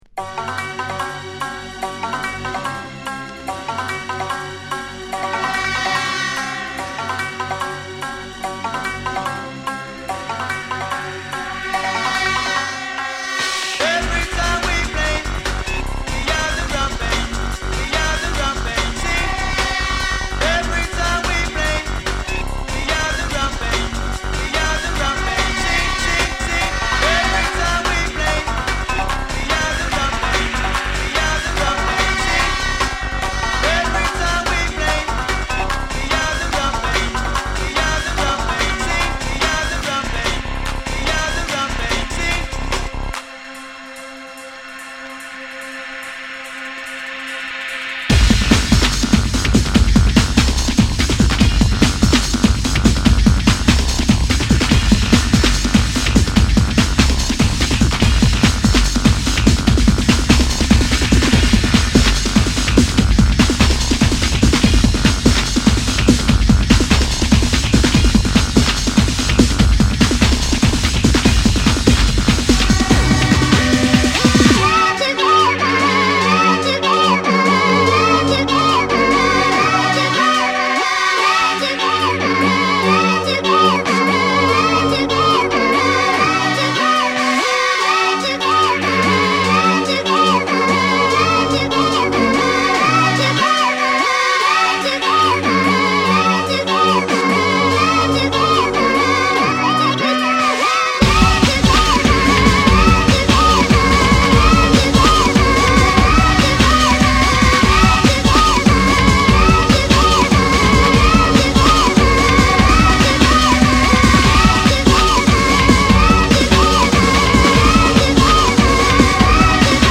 レイヴテクノ・クラシック